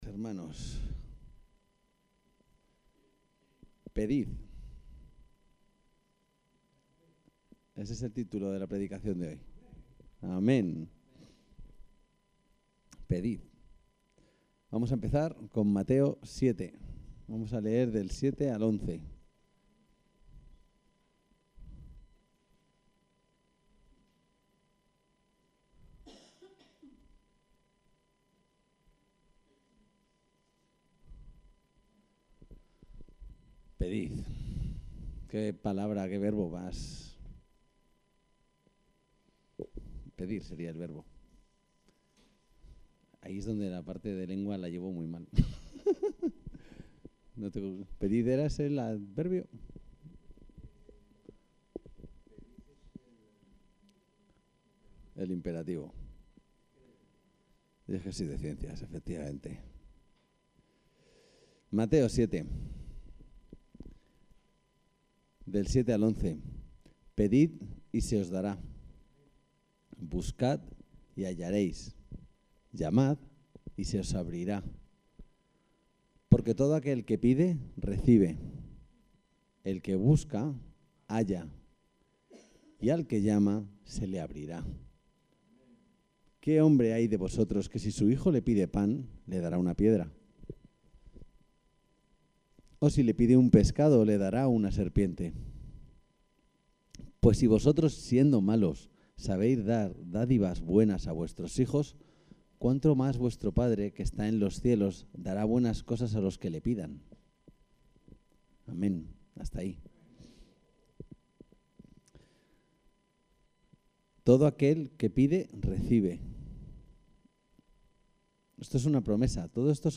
El texto de la predicación se puede leer aquí: Pedid